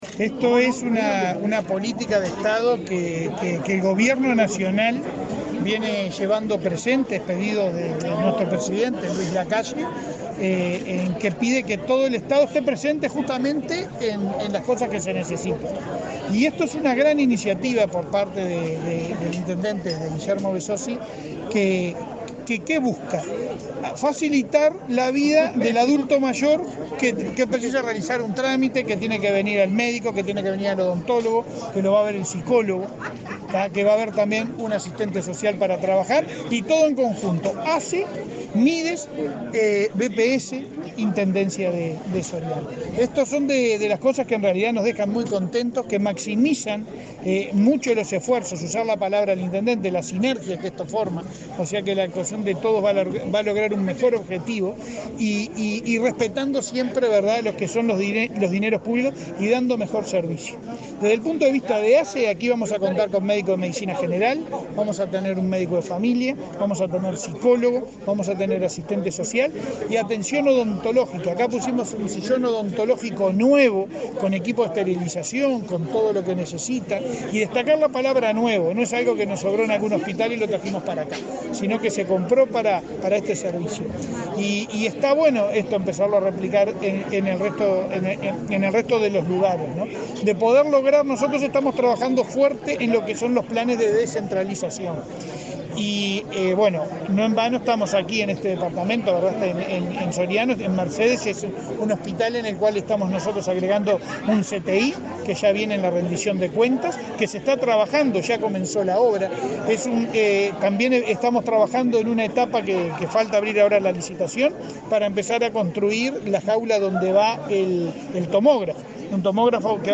Declaraciones del presidente de ASSE, Leonardo Cipriani
El presidente de ASSE, Leonardo Cipriani, dialogó con la prensa en Soriano, donde inauguró un centro de referencia para el adulto mayor y dos